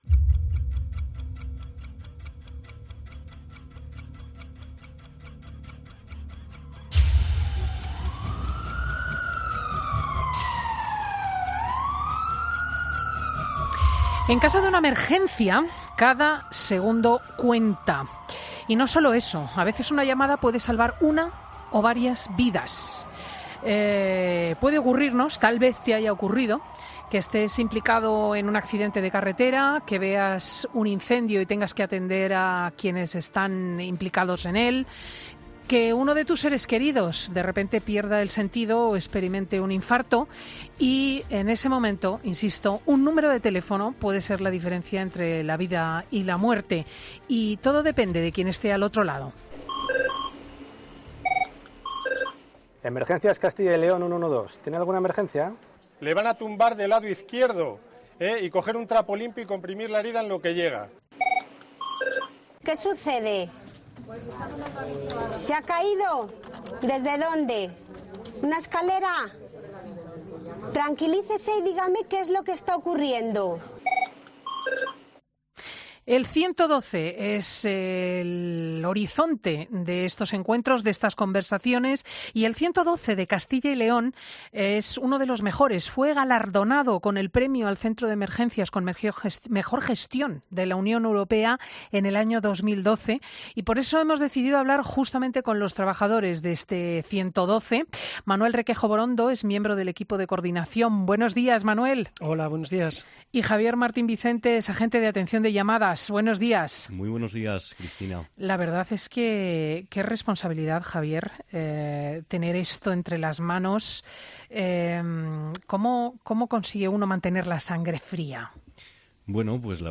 Dos trabajadores de la Delegación en Castilla y León nos cuentan cómo es su trabajo detrás del teléfono de emergencia